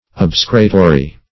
Search Result for " obsecratory" : The Collaborative International Dictionary of English v.0.48: Obsecratory \Ob"se*cra*to*ry\, a. Expressing, or used in, entreaty; supplicatory.